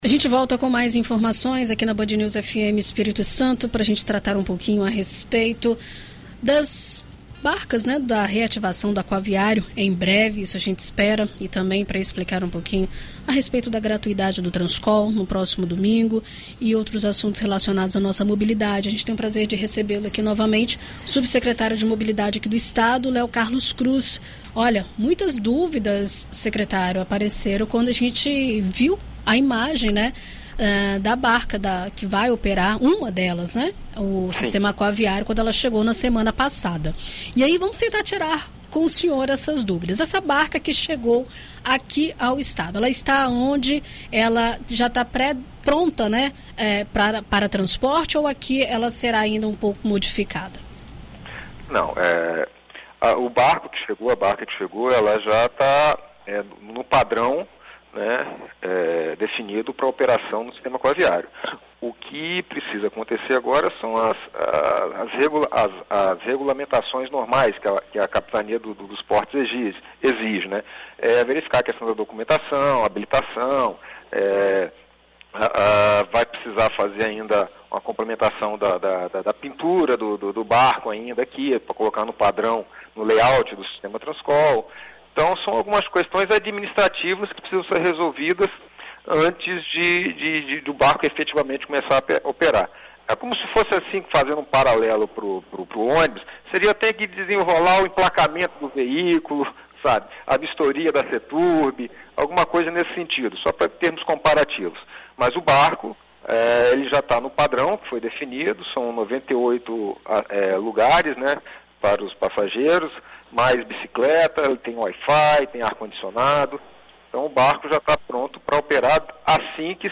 Em entrevista à BandNews FM Espírito Santo nesta segunda-feira (24), o subsecretário de Estado de Mobilidade, Léo Carlos Cruz, explica como funcionará o esquema de balças na região metropolitana e detalha as características das embarcações.